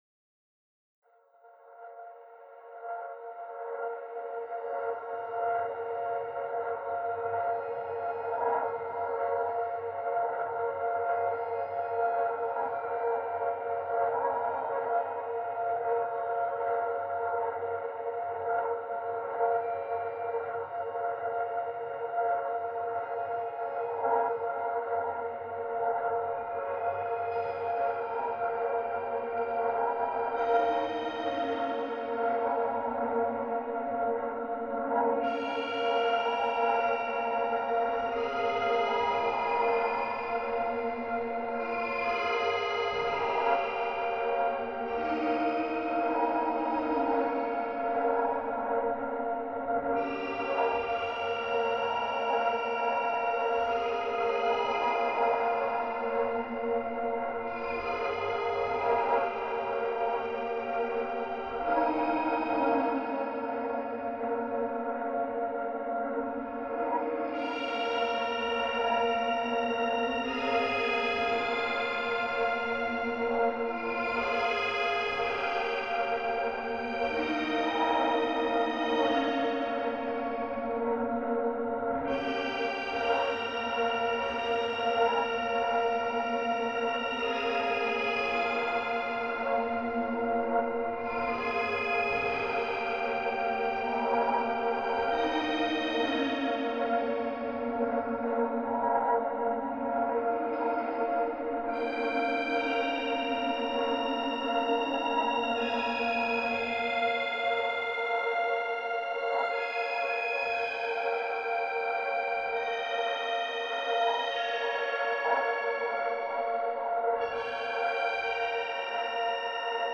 Strange supernatural soundscape.